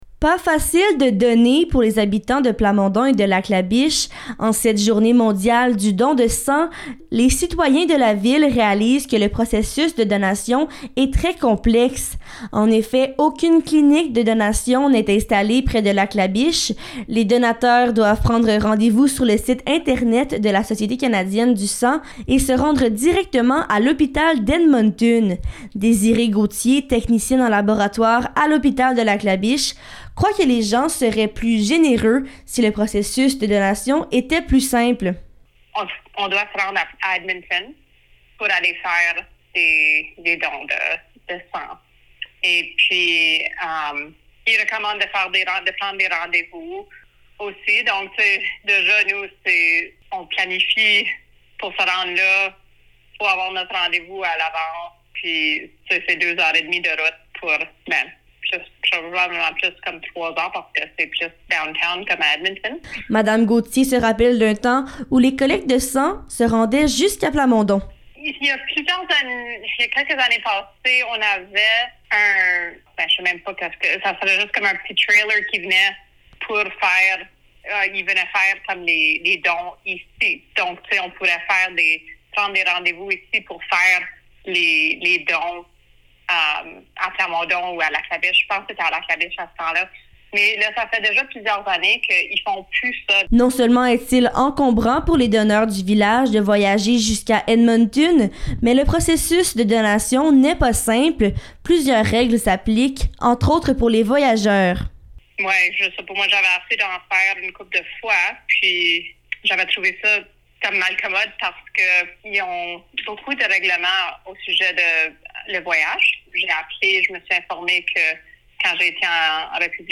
Reportage-Don-de-Sang.mp3